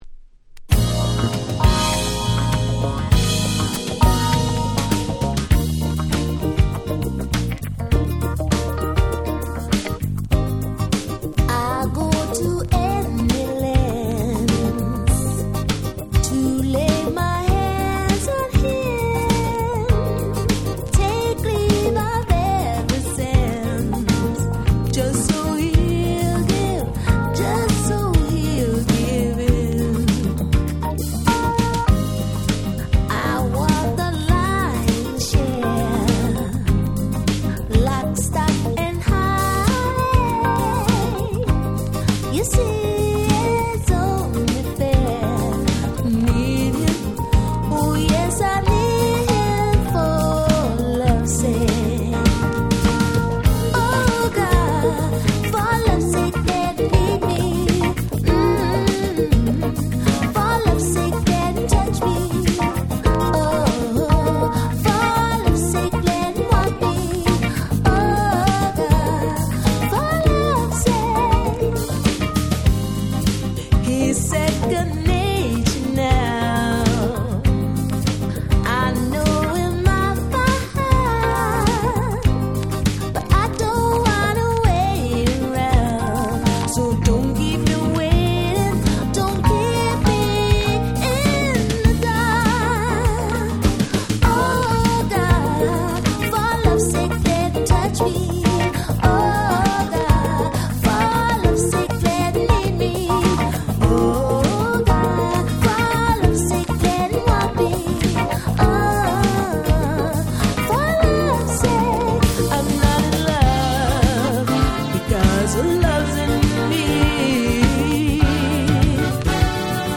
95' Very Nice UK Soul / Synthpop !!
爽やかで温かいポップナンバーで当時はJ-Waveなんかでも頻繁にPlayされていた記憶です。
90's R&B